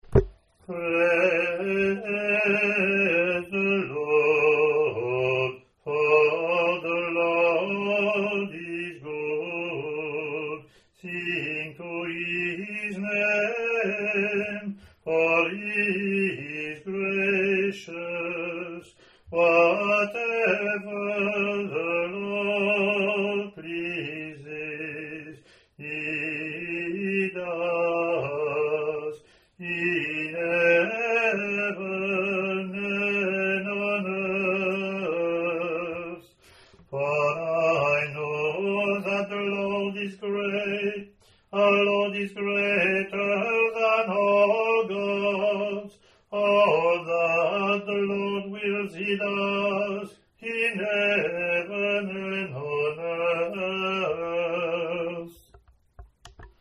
English antiphon – English verseLatin antiphon)